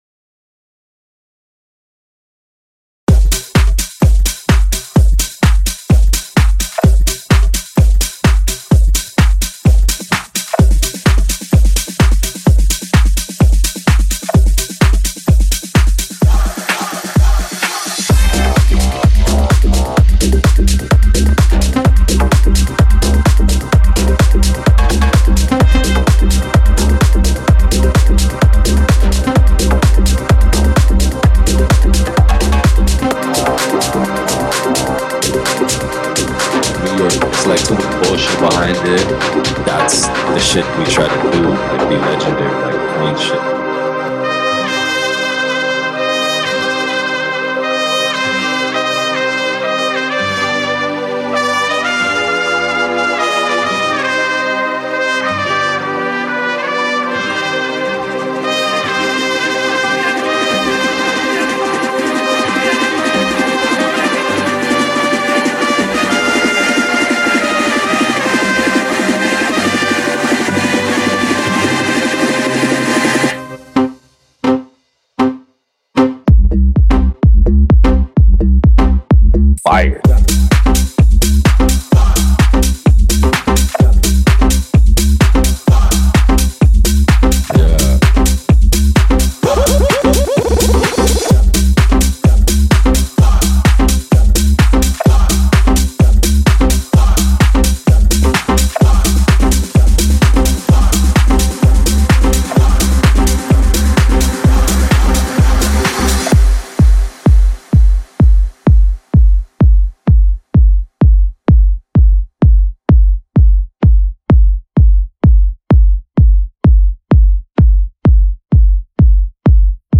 Everything is on-beat from start to finish.